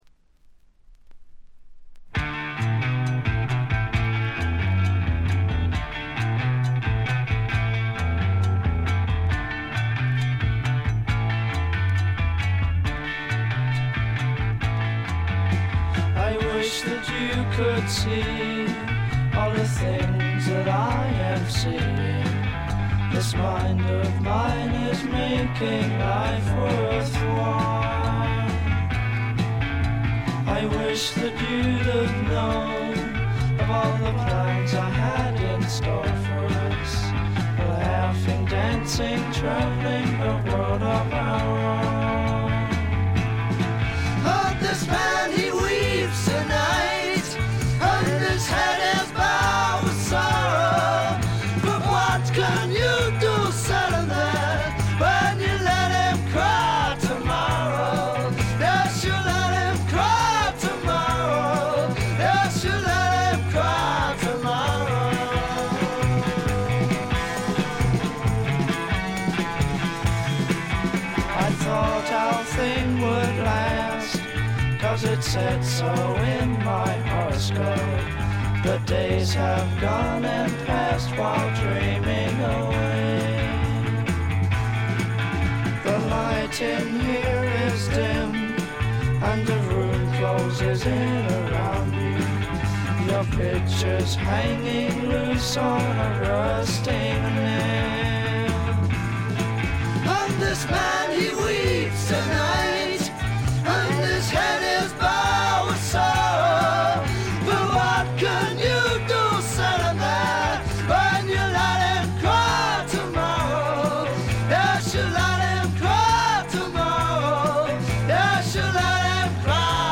チリプチがちらほら。
試聴曲は現品からの取り込み音源です。